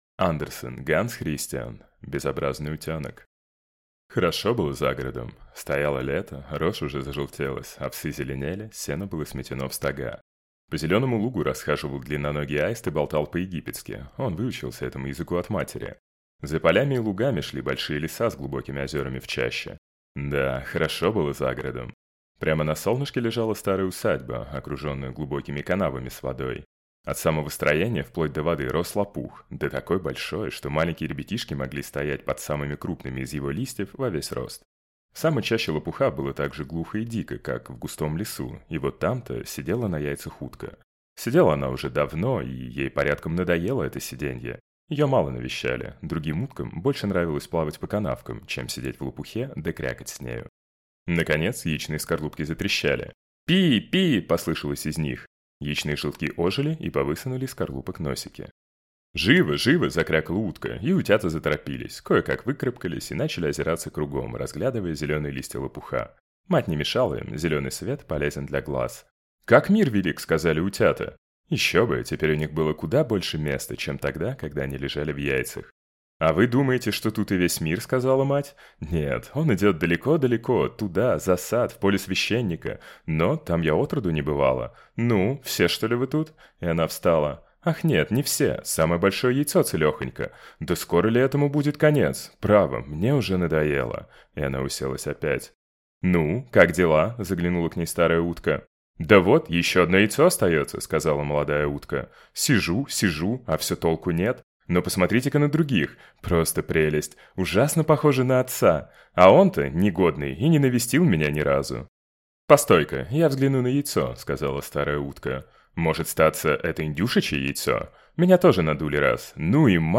Аудиокнига Безобразный утёнок | Библиотека аудиокниг
Прослушать и бесплатно скачать фрагмент аудиокниги